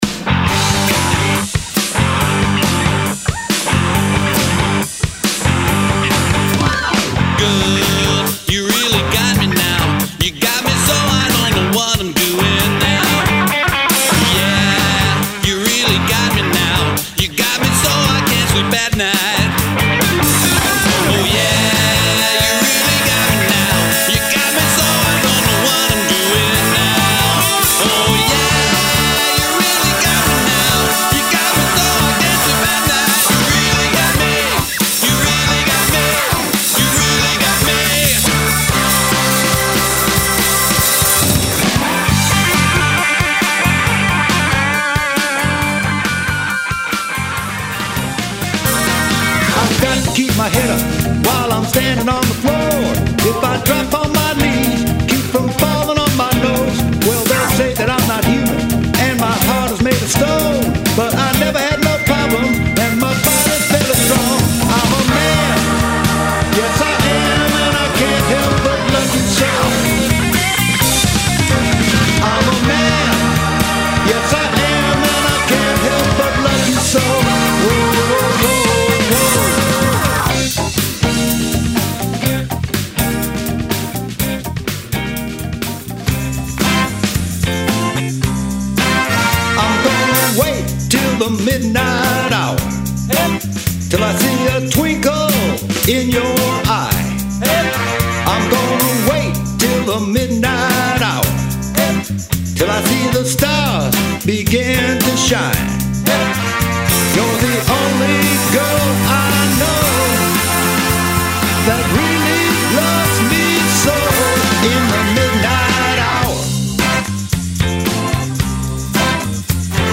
The Hairy Canary Band - 1996 - Austin, Texas
This is the band that should have been really kickin' it out, but strangely only made into the studio to record a demo.
A 4 piece rock band